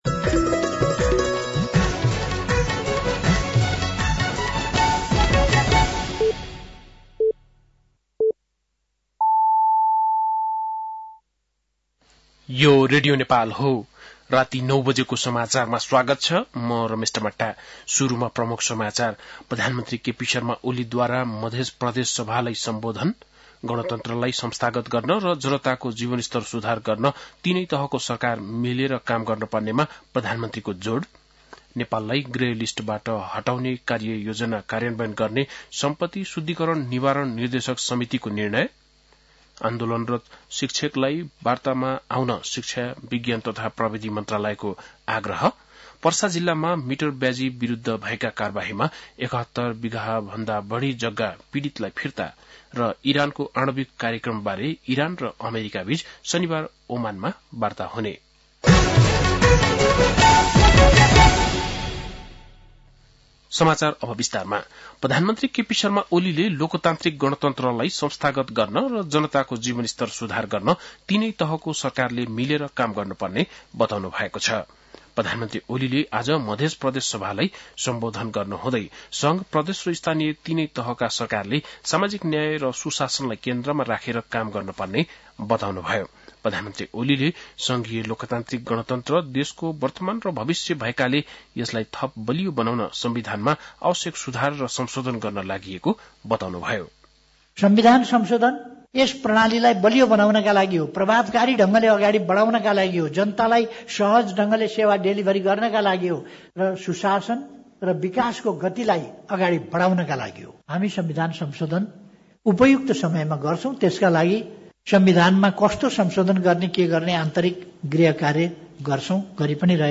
बेलुकी ९ बजेको नेपाली समाचार : २६ चैत , २०८१